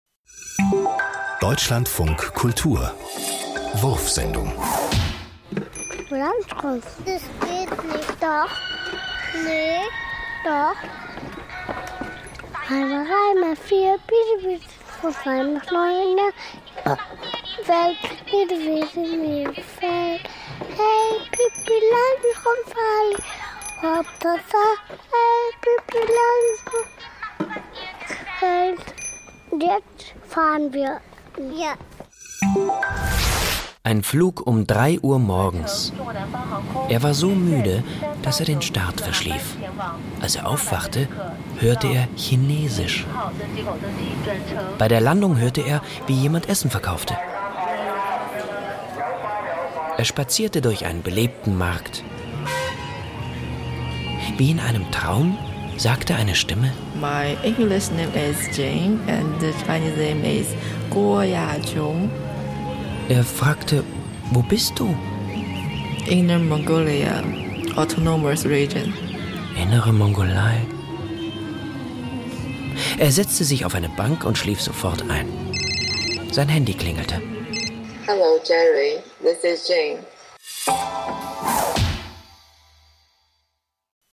Hörspiel und Feature